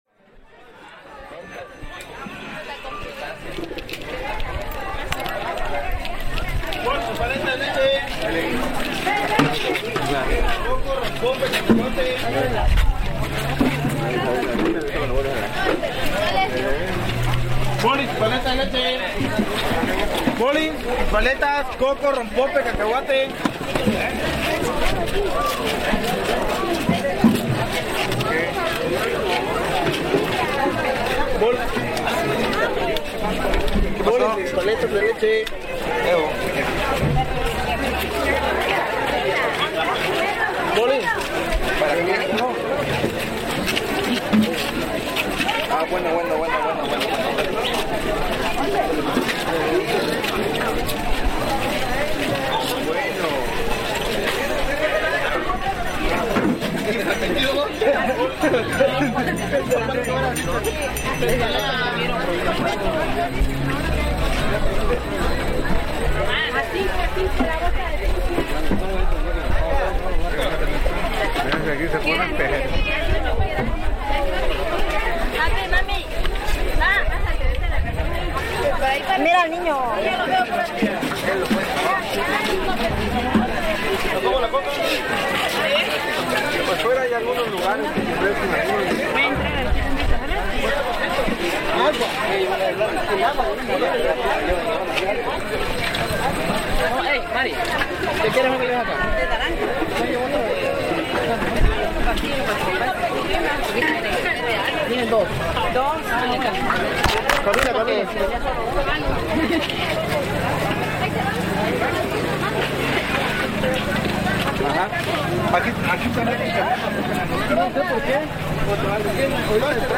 Vendedores en el panteón
En las afueras del Panteón Muncipal de Tuxtla Gutierrez, Chiapas; se cierran las calles que rodean el lugar y se instalan vendedores principalmente de flores, alimentos, bebidas, que ofrecen a la gran cantidad de personas que llegan para limpiar y dejar flores a las tumbas de sus familiares.
Equipo: Grabadora Sony ICD-UX80 Stereo